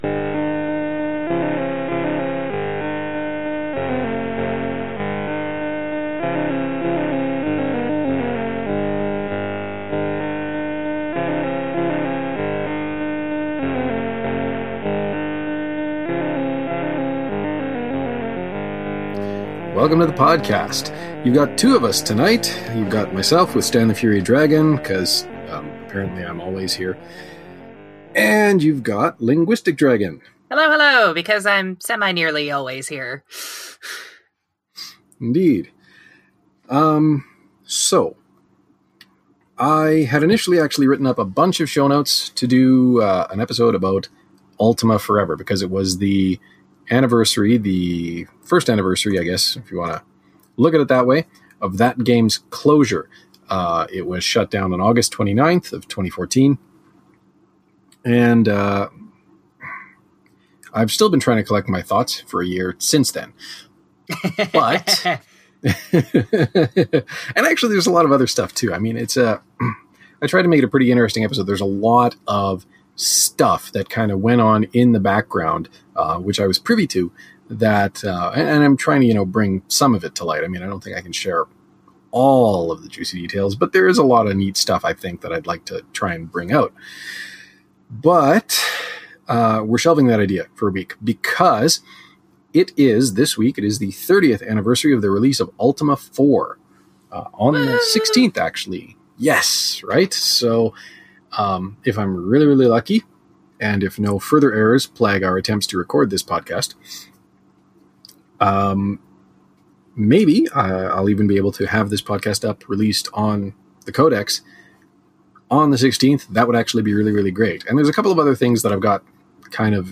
(Also, I think I used the wrong microphone for recording.)